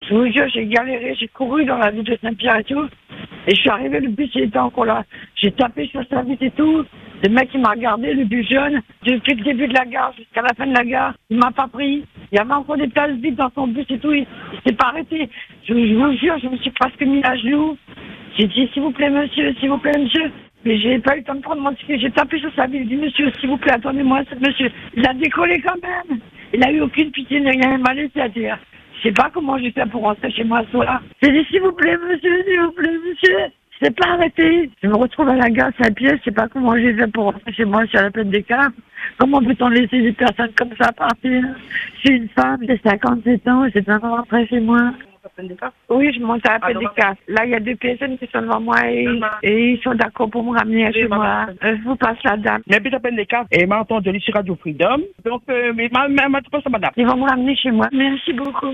Désemparée, sans savoir comment rentrer chez elle, cette auditrice appelle notre antenne.
En direct sur Radio Free Dom, des auditeurs entendent son appel, réagissent immédiatement et proposent de la raccompagner chez elle.